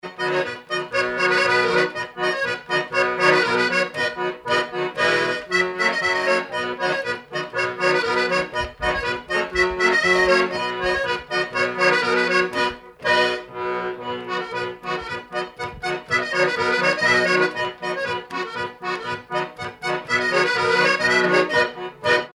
danse : monfarine, montfarine, montferrine, montferine
circonstance : bal, dancerie
Pièce musicale inédite